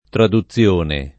traduzione